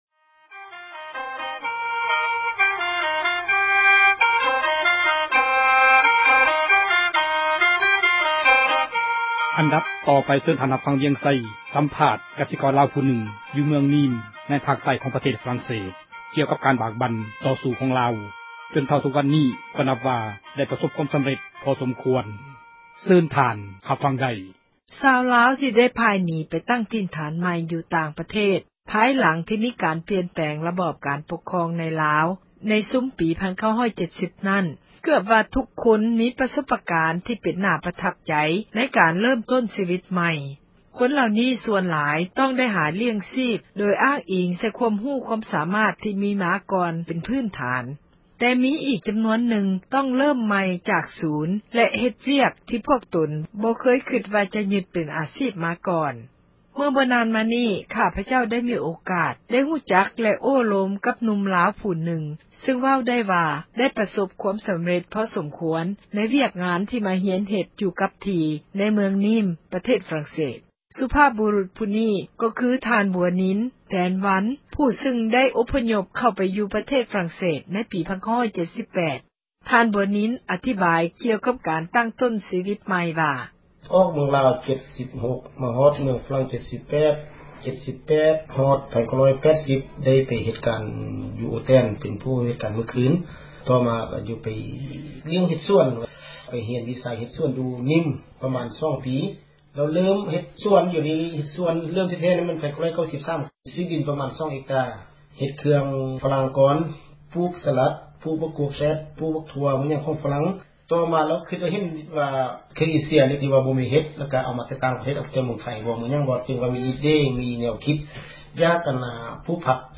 ສຳພາດກະສິກອນລາວຜູ້ນຶ່ງ